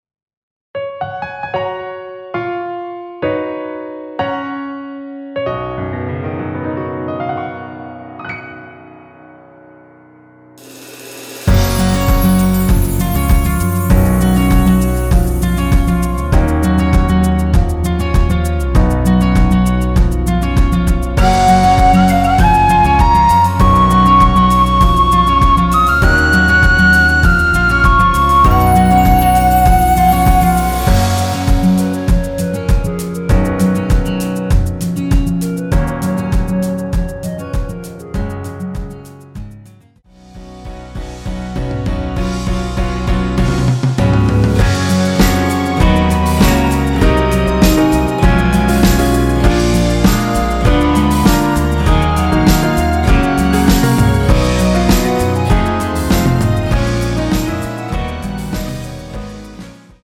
원키에서(-2)내린 멜로디 포함된 MR입니다.(미리듣기 확인)
F#
앞부분30초, 뒷부분30초씩 편집해서 올려 드리고 있습니다.